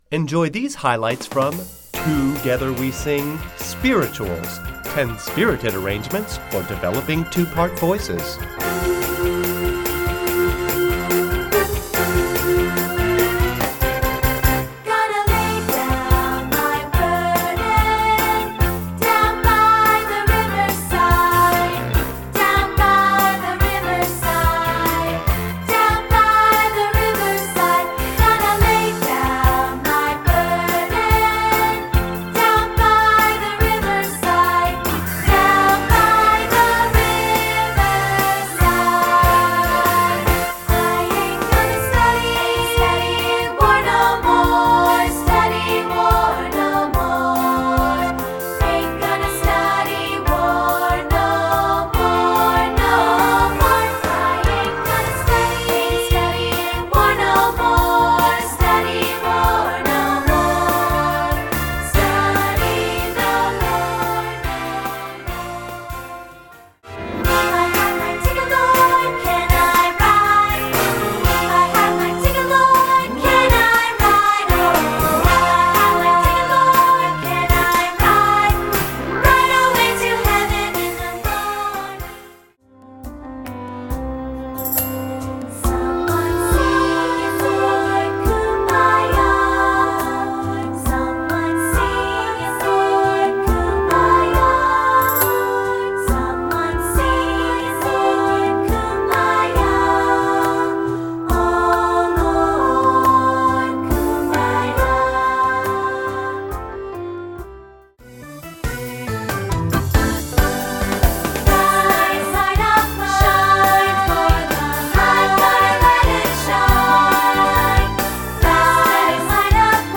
Composer: Spirituals